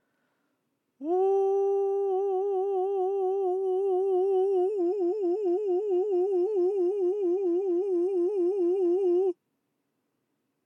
音量注意！
「喉頭を下げて発声しました」という方がよく陥りがちな舌で喉頭を押し下げて発声した間違いパターンです。